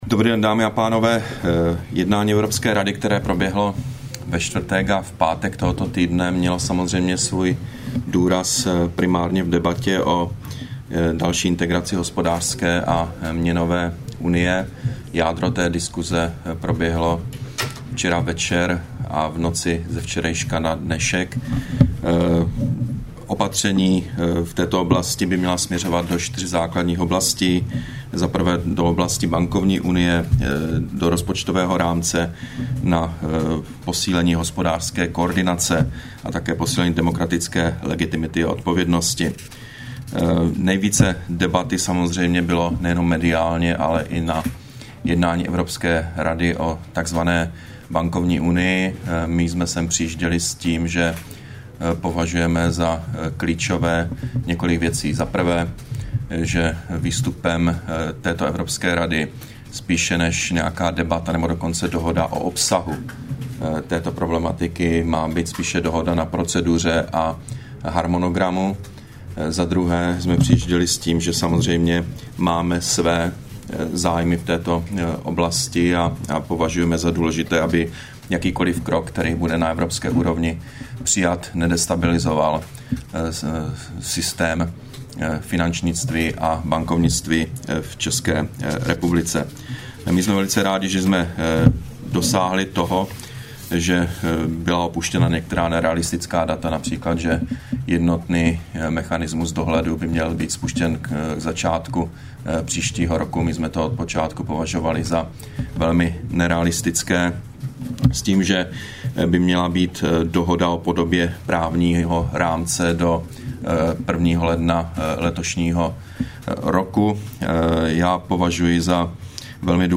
Brífink premiéra Petra Nečase po jednání Evropské rady, 19. října 2012